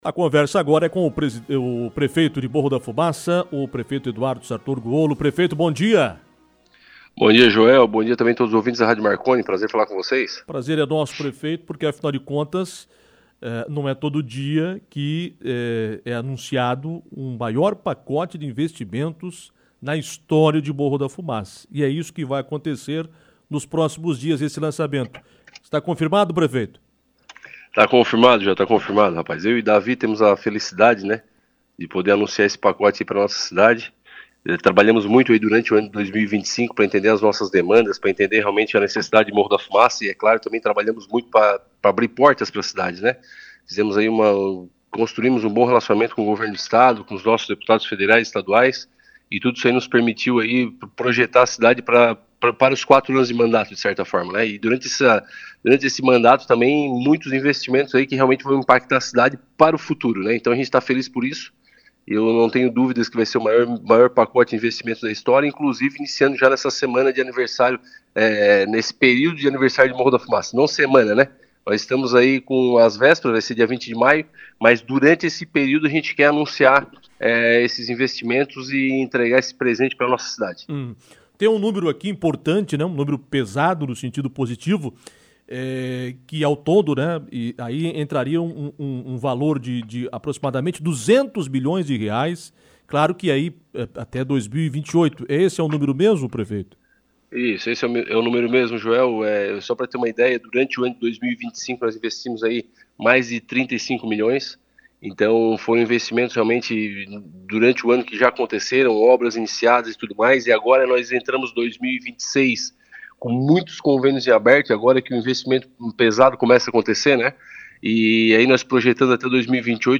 Em entrevista, o prefeito Eduardo Sartor Guollo (PP) destacou que a administração trabalhou no último ano construindo um bom relacionamento com o Governo do Estado e com os parlamentares catarinenses.